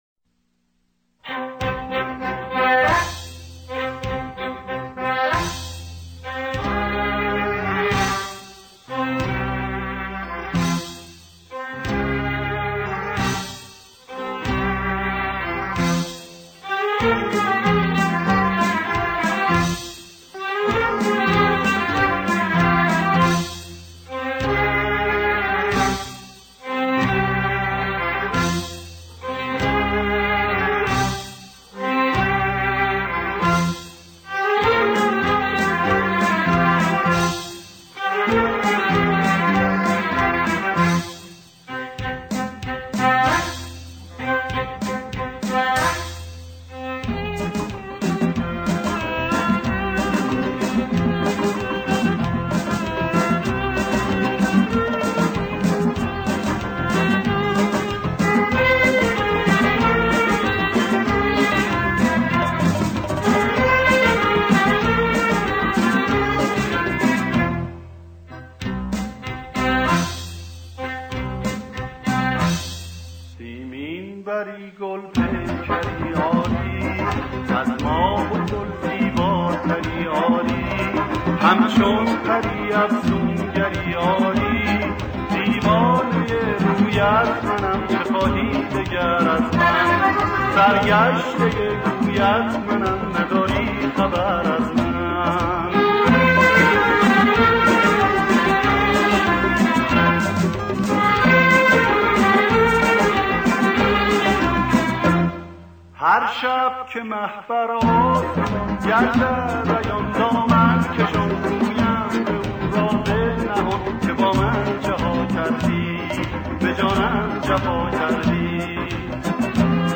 دانلود ریمیکس همین موزیک